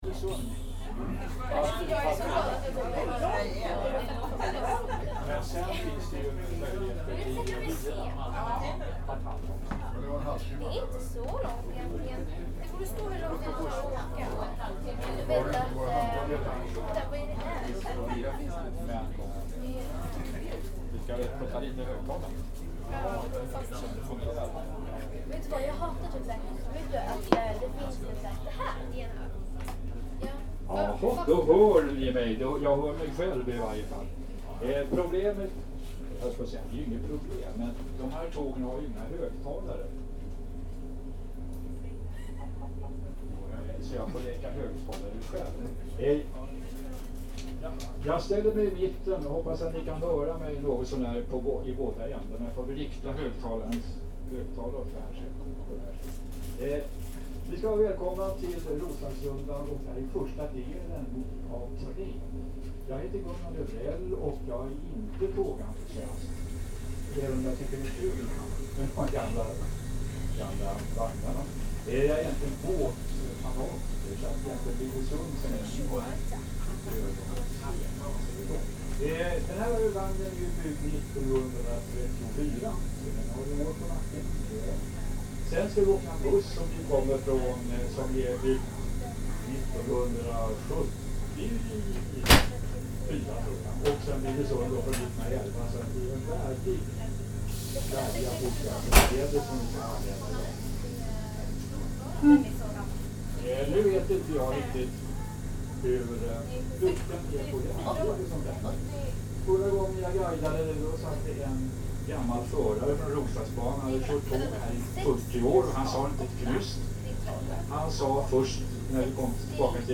This is a train built in 1934 and used up until the 1990s - and in this version of the soundscape there is a crowd going with the train alongside a guide.
• Train
• Railroad Category: Railway